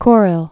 (krĭl, k-rēl)